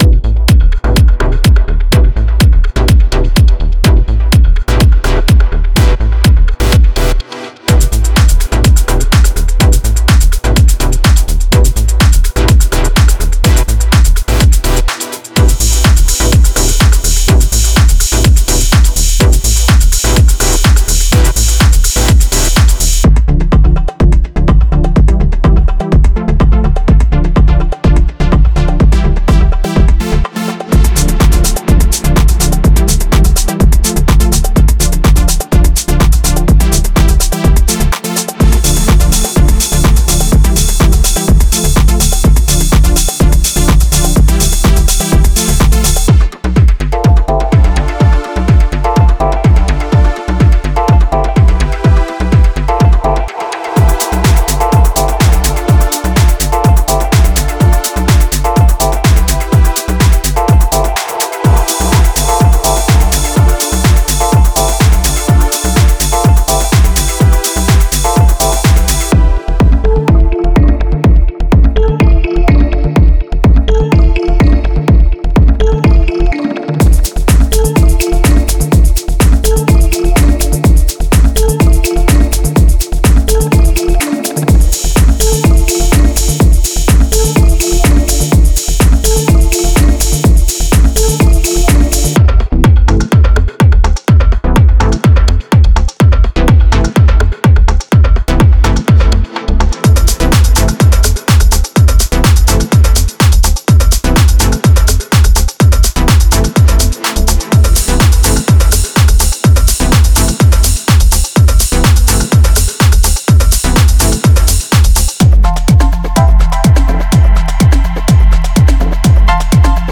Genre:Melodic Techno
鋭くダイナミックなドラムループは力強いグルーヴを生み出し、温かく力強いベースループはトラックの骨格を形成します。
デモサウンドはコチラ↓
95 Drum loops (Full, Kick, Clap, Hihat, Perc, Ride)
10 Vox loops